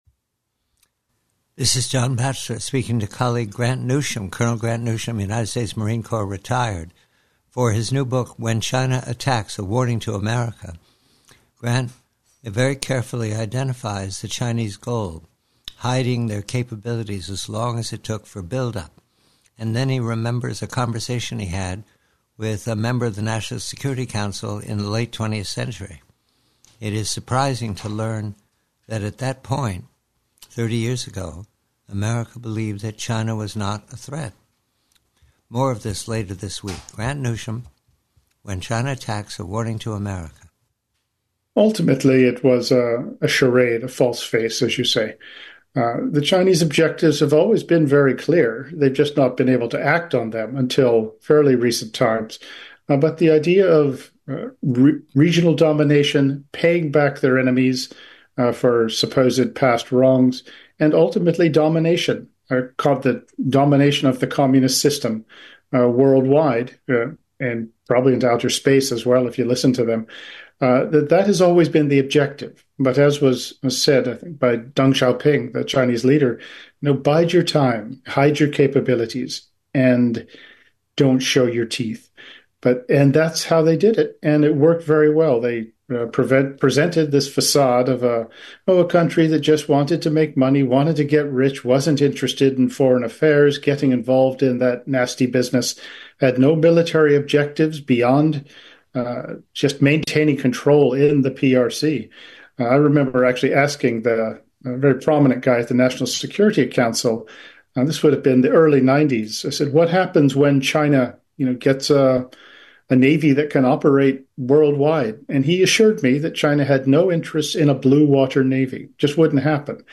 PREVIEW:: PRC:US: